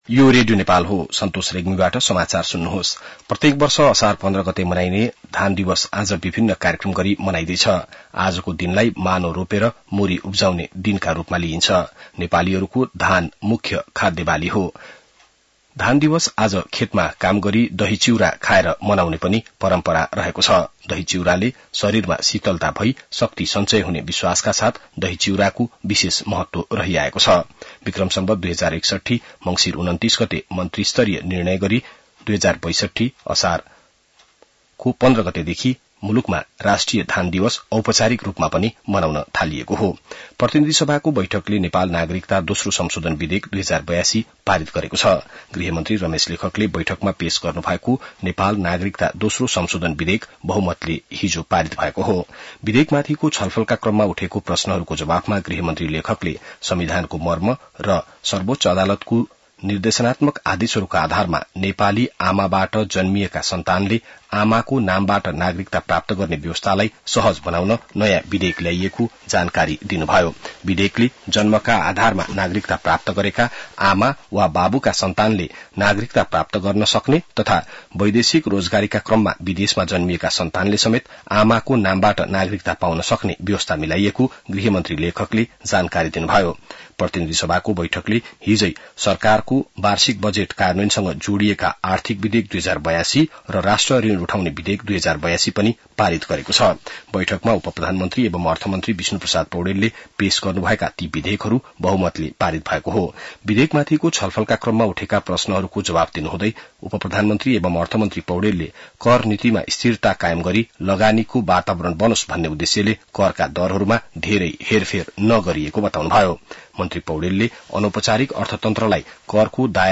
An online outlet of Nepal's national radio broadcaster
बिहान ६ बजेको नेपाली समाचार : १५ असार , २०८२